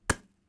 impact_small2.wav